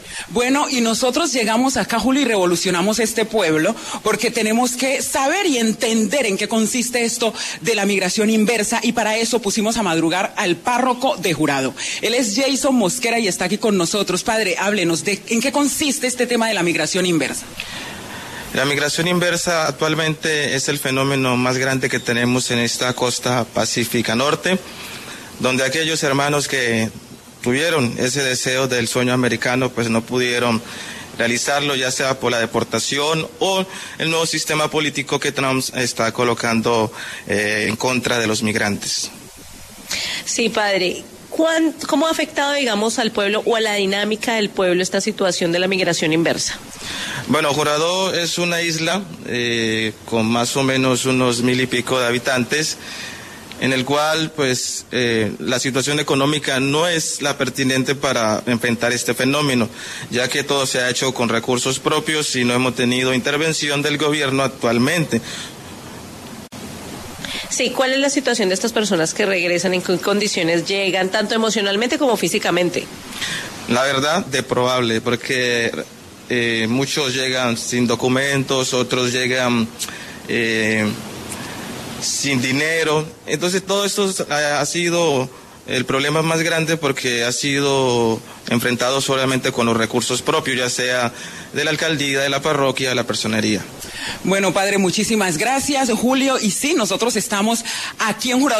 pasó por los micrófonos de La W y habló sobre la migración inversa en este municipio